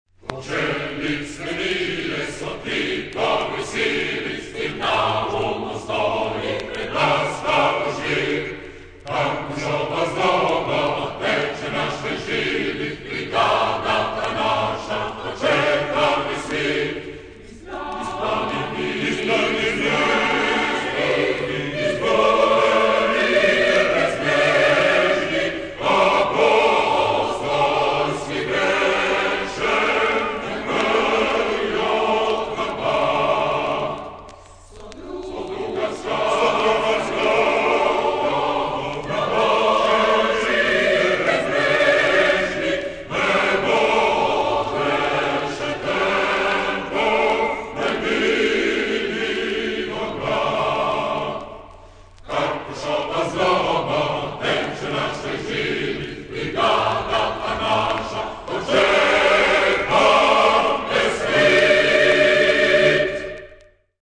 Марши
Описание: Походная песня партизан - сторонников тов.